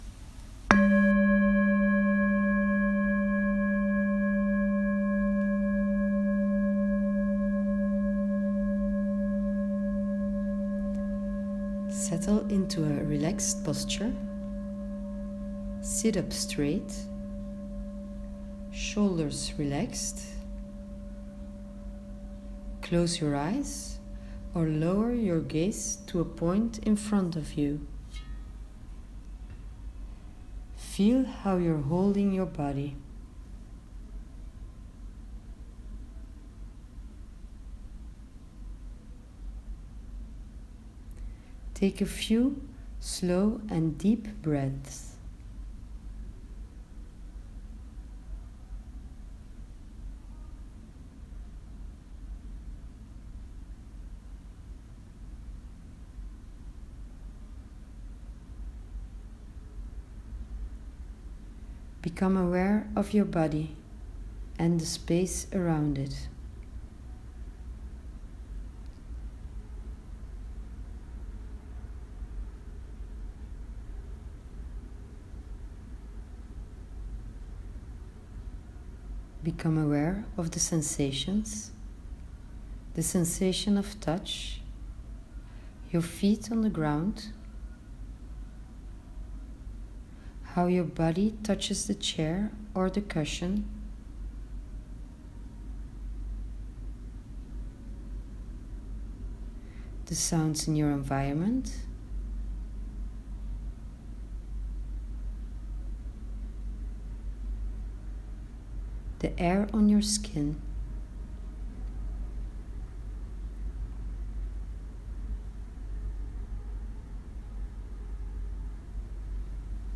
Meditation for Saturday 16/12